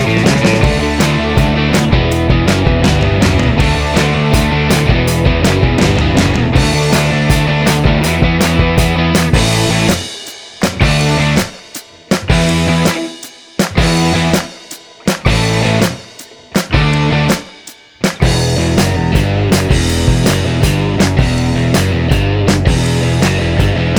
No Backing Vocals Rock 'n' Roll 2:52 Buy £1.50